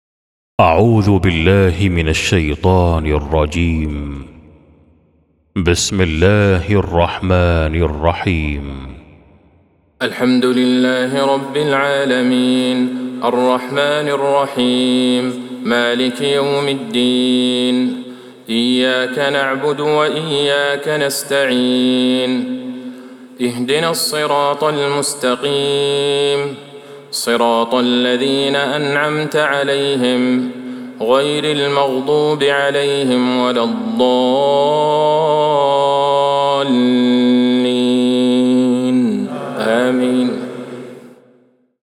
سورة الفاتحة Surat-Al-Fatiha > مصحف تراويح الحرم النبوي عام 1447هـ > المصحف - تلاوات الحرمين